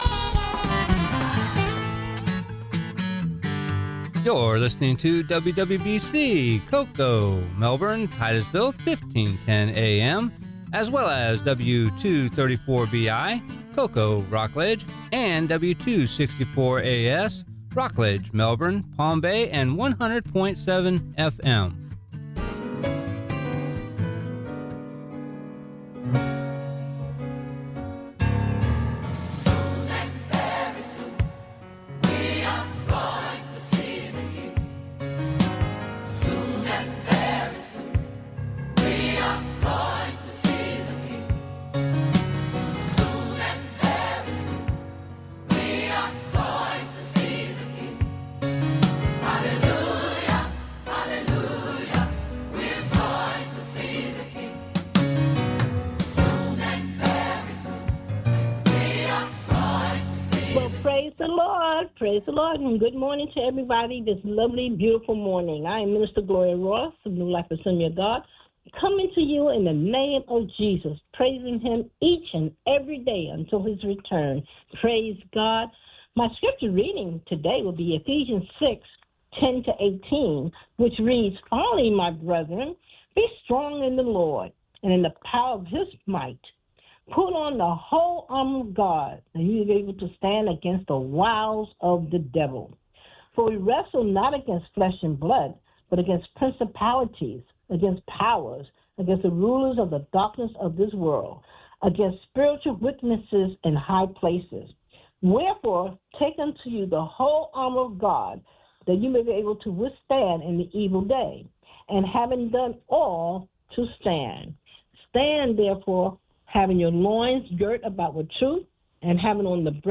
Sermon: Moses and The Name of God Part 2 Exodus Ch. 3